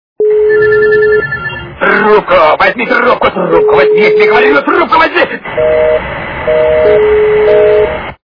» Звуки » Смешные » Сумашедший телефон - Трубку возьми, возьми трубку!